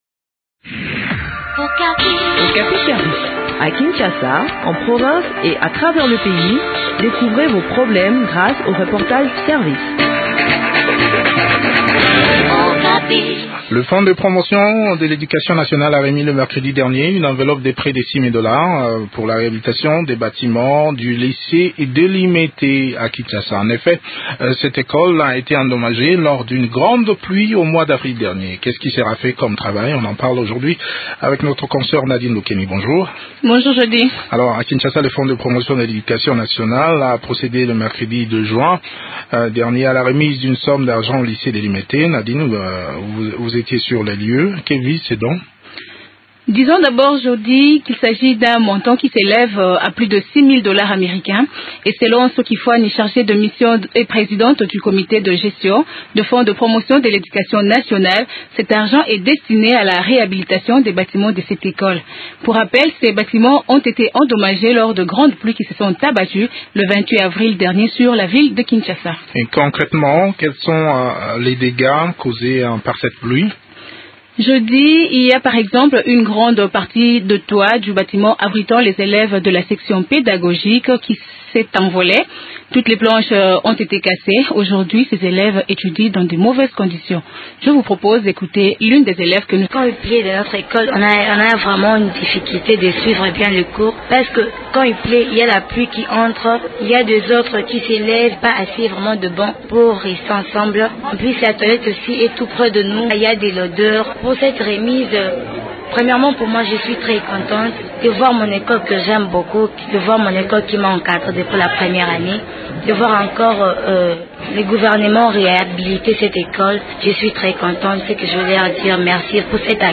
Des précisions dans cet entretien